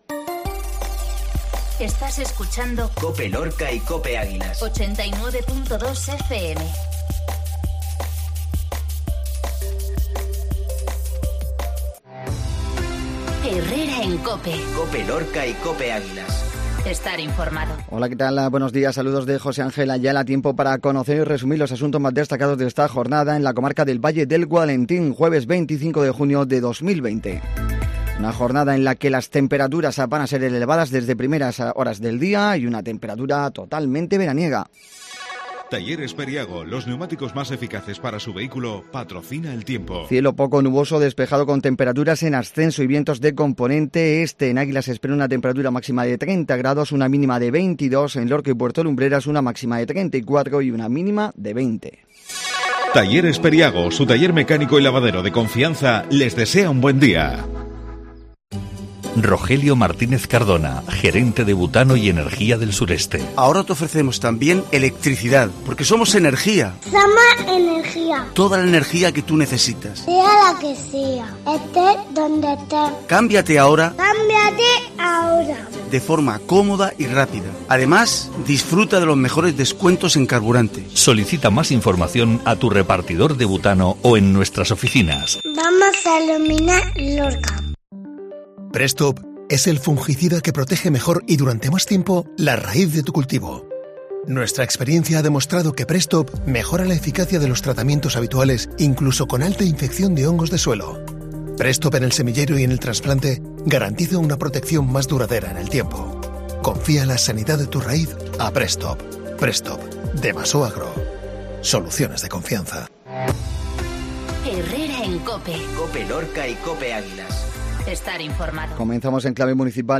INFORMATIVO MATINAL JUEVES